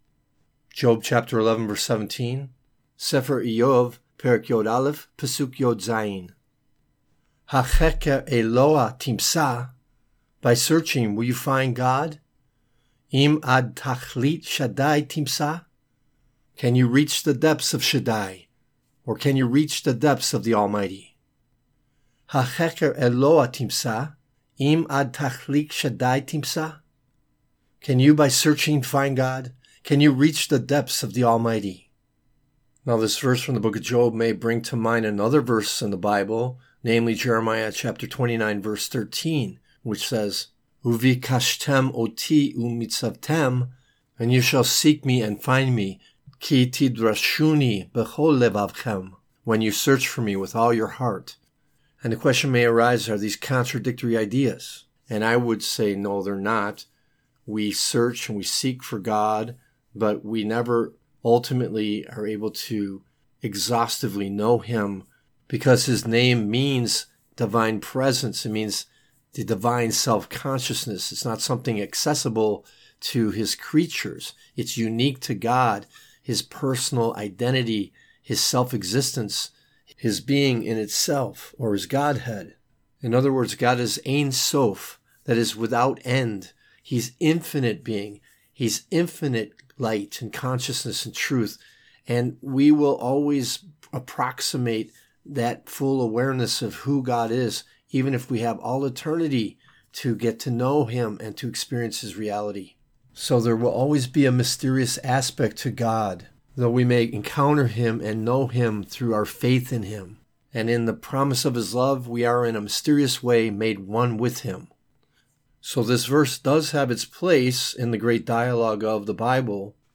Hebrew Lesson